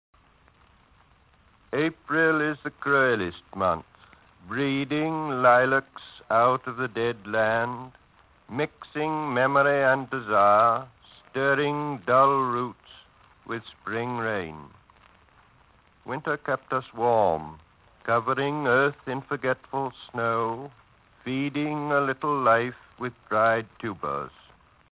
Eliot legge l'incipit di The Waste Land